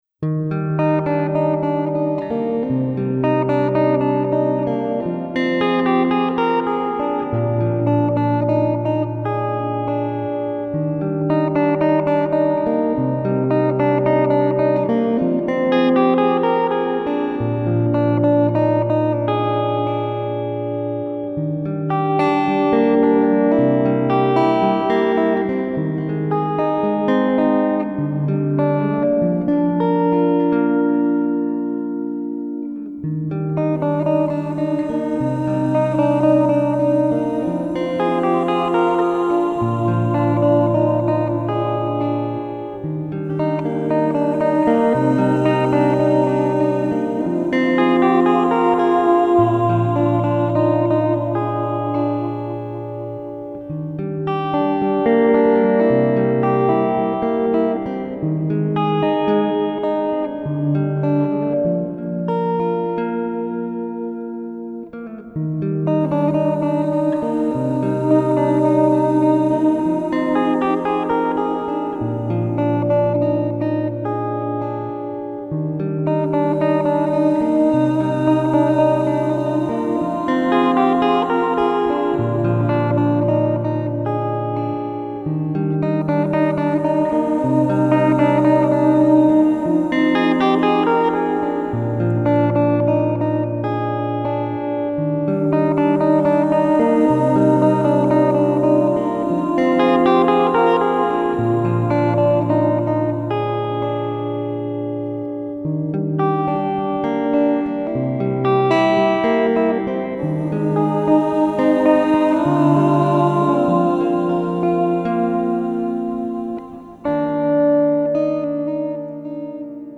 【吉他氛围音乐】
专辑风格：New Age
是动人的旋律很梦幻般的宁静!